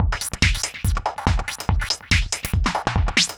Index of /musicradar/uk-garage-samples/142bpm Lines n Loops/Beats
GA_BeatAFilter142-13.wav